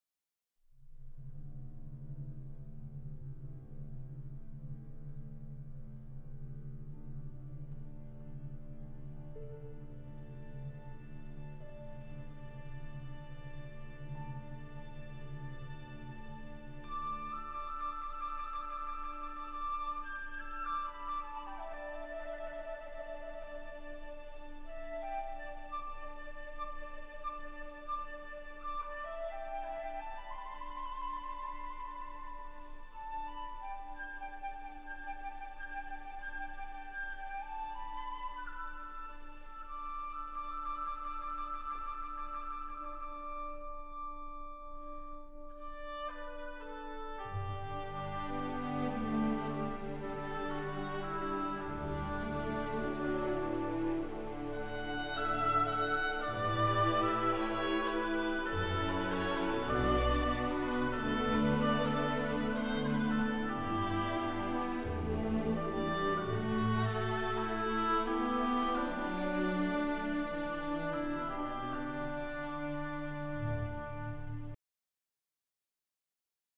音色鲜艳无匹，透出一种迷人的光泽，而且音量宏伟